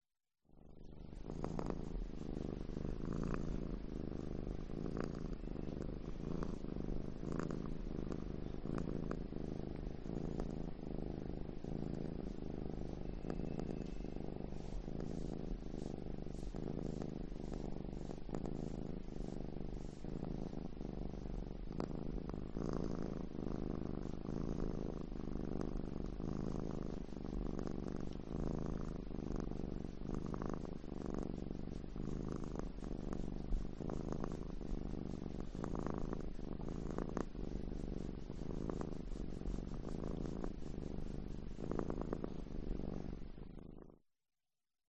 Звуки кошек
Мурлыканье кошки когда гладят